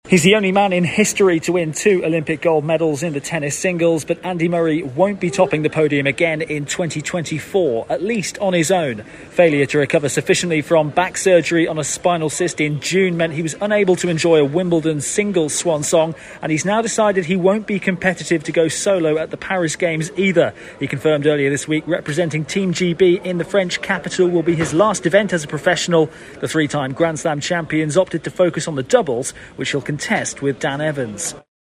reports on Andy Murray from Paris.